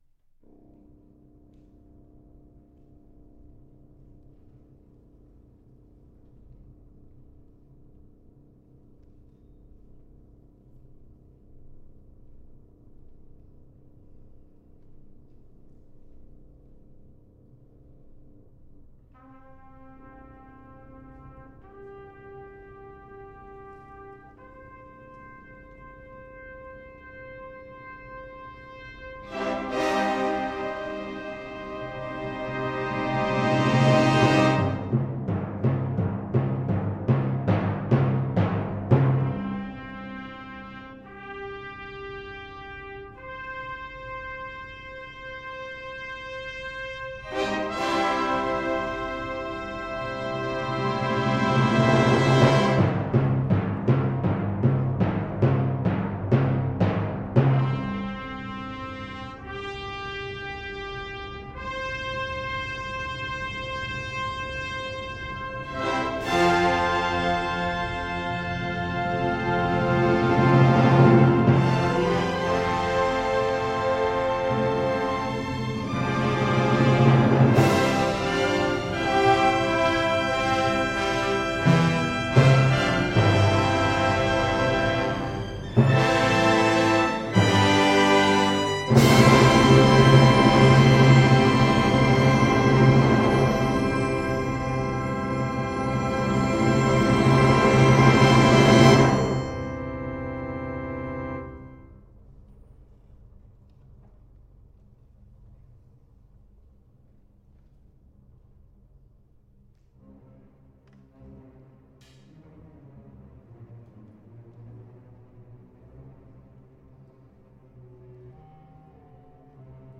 Orchestra version
Style: Classical